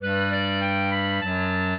clarinet
minuet1-7.wav